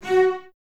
Index of /90_sSampleCDs/Miroslav Vitous - String Ensembles/Cellos/CES Stacc